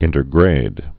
(ĭntər-grād)